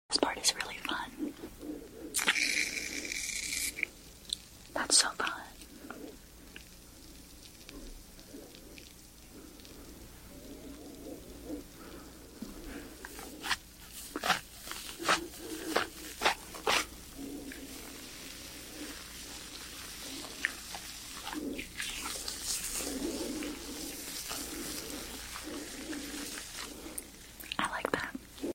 she’s so good with ASMR sound effects free download